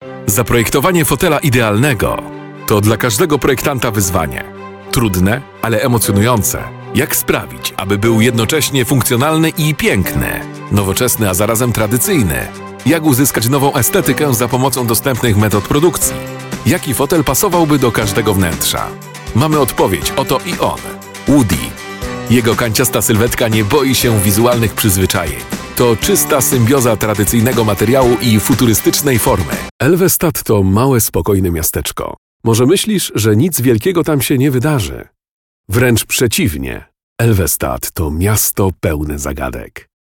deep Polish male voice artist
Male 30-50 lat
Narracja lektorska z udźwiękowieniem